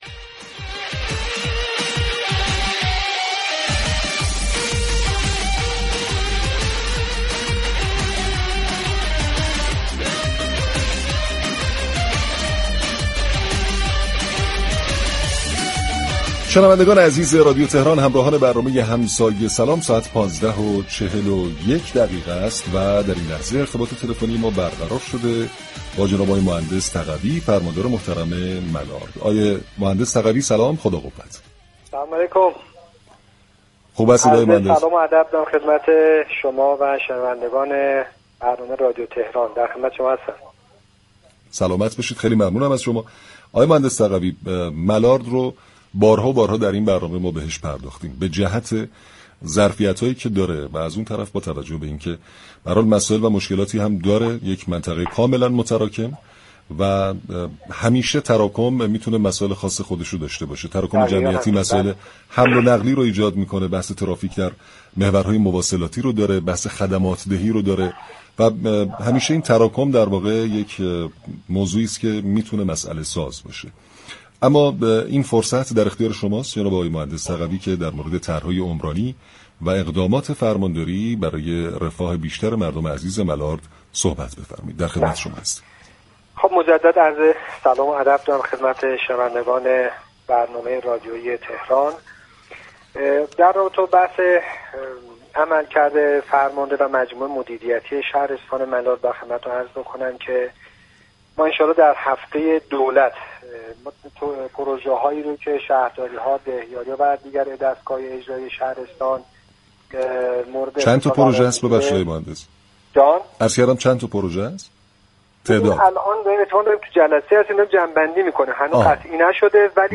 فرماندار ملارد در گفت‌وگو با رادیو تهران از پیشرفت پروژه‌های عمرانی متعدد این شهرستان از جمله افتتاح فرهنگسرا، توسعه زیرساخت‌های آب و تعریض معابر ترافیكی خبر داد و تأكید كرد: این اقدامات با هدف ارتقای رفاه و كیفیت زندگی شهروندان تا پایان سال ادامه دارد.